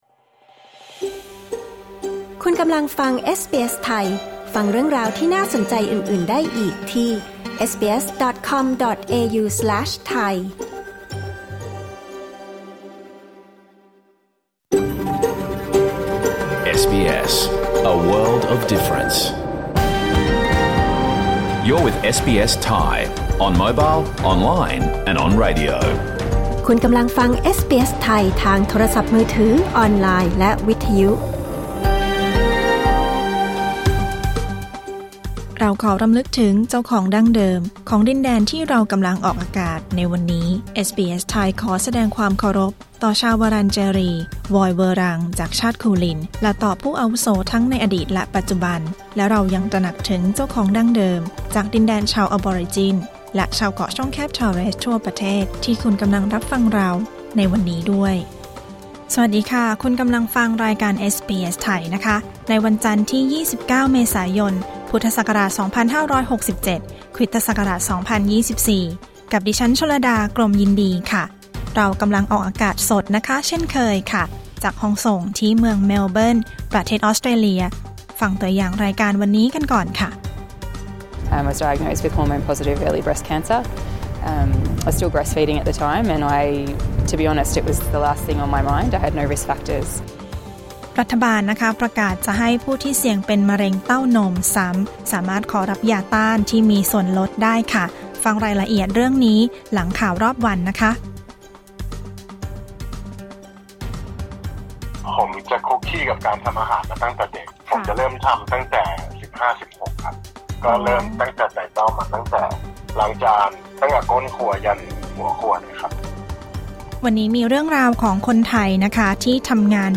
รายการสด 29 เมษายน 2567